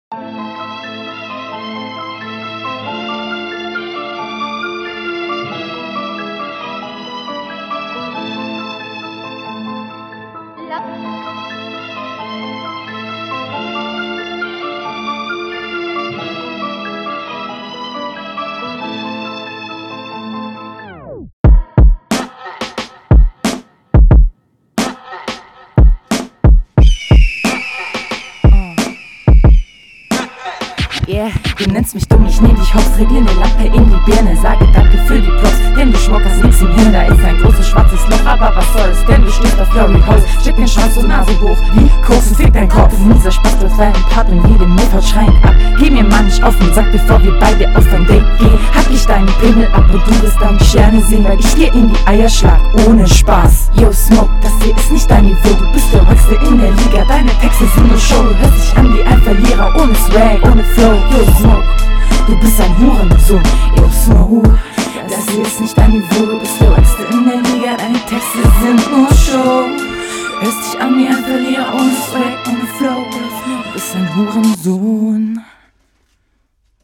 Flow direkt sauberer, da überwiegen die onpoint Stellen die offbeat Stellen.
Du bist extrem leise und mega unsynchron abgemischt. Es ist wirklich schwer dich zu verstehen.